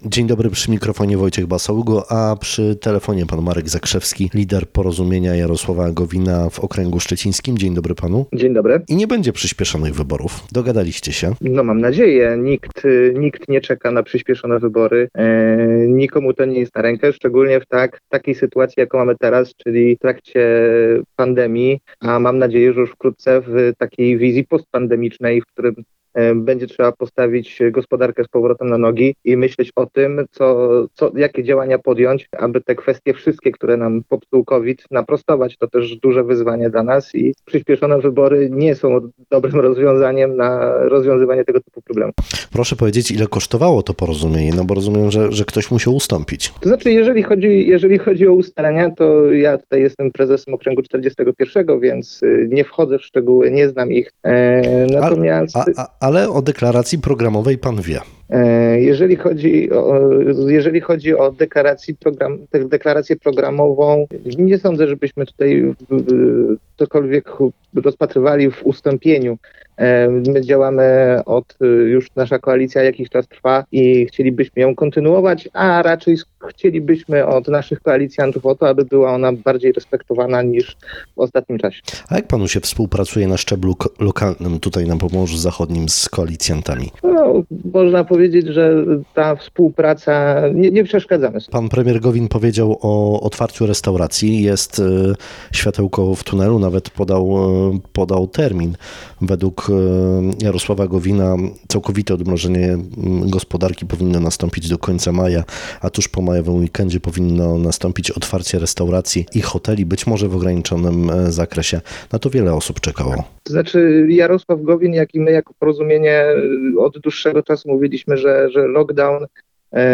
Nasz dzisiejszy Gość Rozmowy Dnia